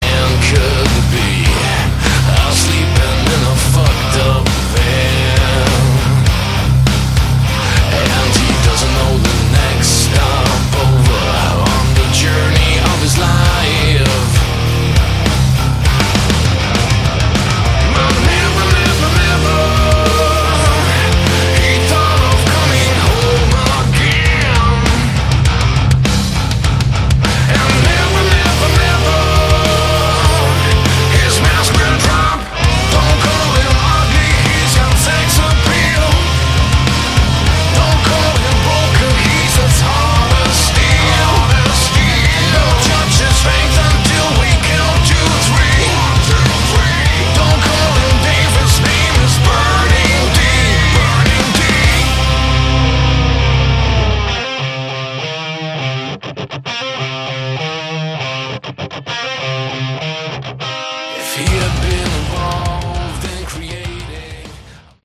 Category: Modern Hard Rock